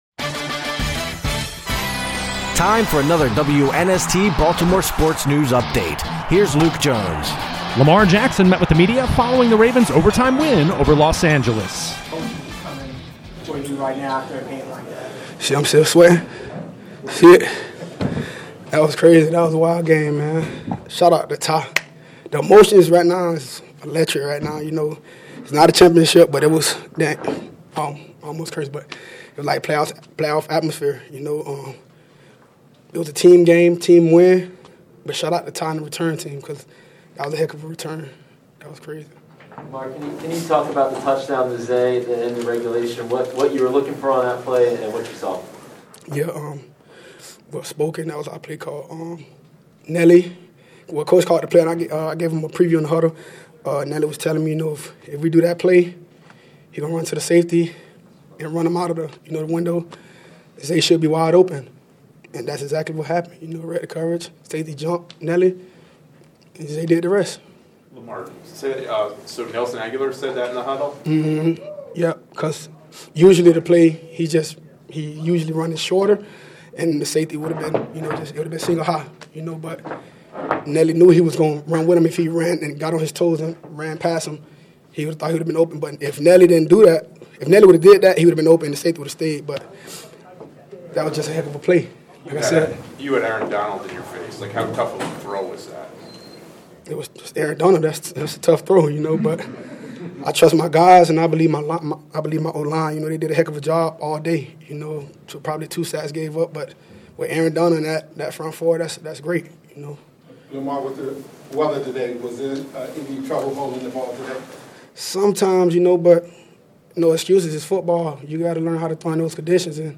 Locker Room Sound